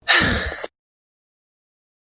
Woman Sigh
Woman Sigh is a free sfx sound effect available for download in MP3 format.
yt_27dkfz86Ooc_woman_sigh.mp3